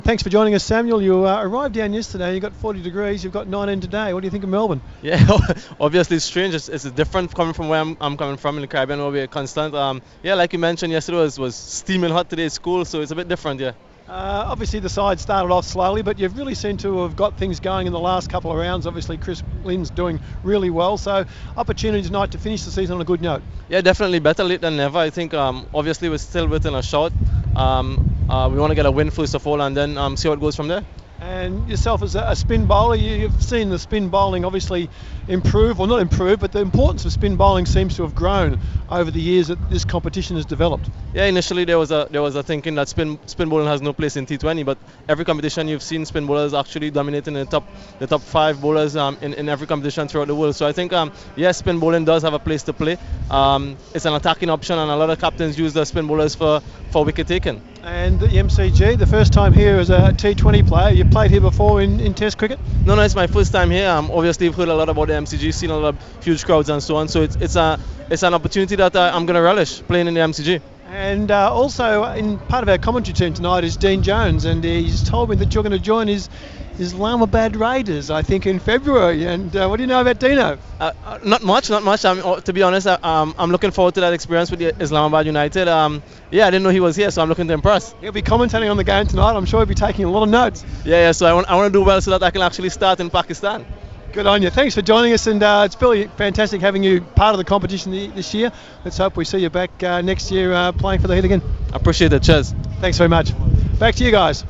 INTERVIEW: Brisbane Heat spinner Samuel Badree speaks ahead of their clash with the Melbourne Stars.